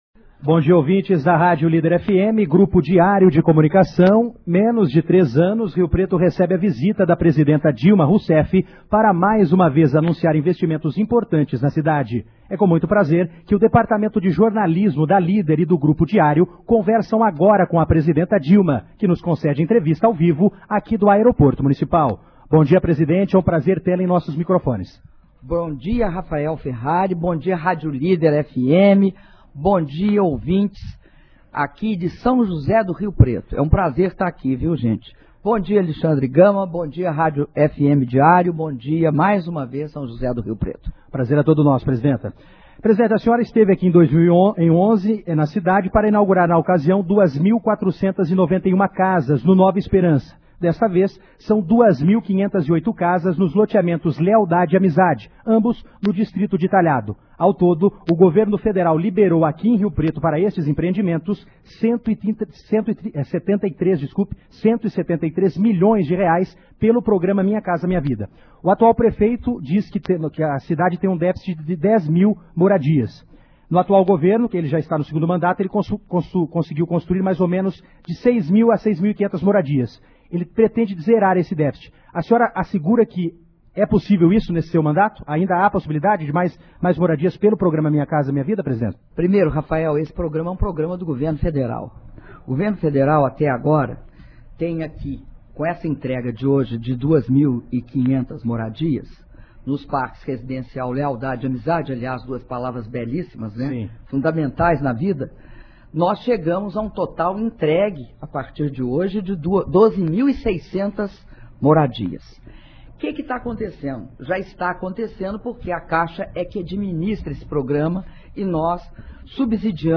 Entrevista concedida pela Presidenta da República, Dilma Rousseff, às rádios FM Diário e Líder FM, de São José do Rio Preto - São José do Rio Preto/SP
São José do Rio Preto-SP, 04 de abril de 2014